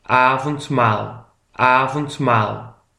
PRONONCIATION :